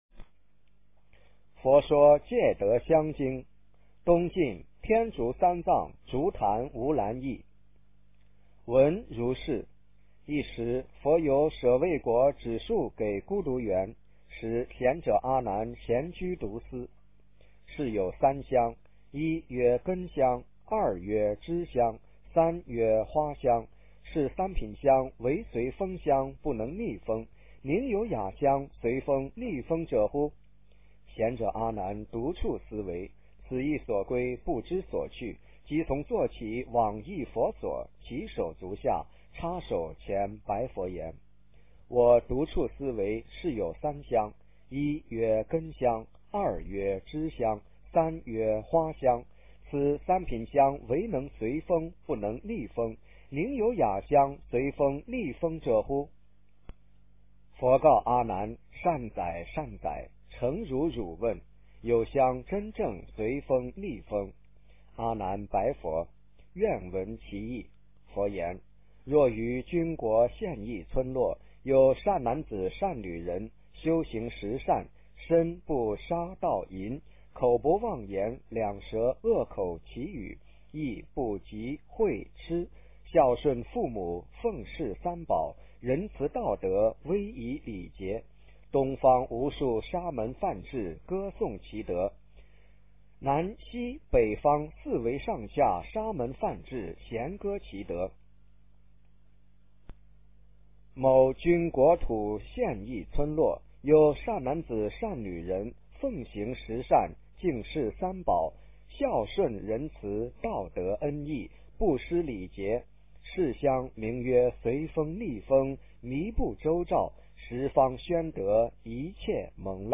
佛说戒德香经 - 诵经 - 云佛论坛